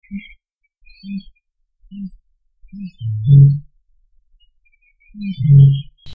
Butor étoilé
Botaurus stellaris
C'est dans cette position qu'il émet un cri qui rappelle le beuglement du boeuf. Le bec ouvert, il aspire de l'air puis, le cou redressé, l'expulse en produisant ce bruit.
butor.mp3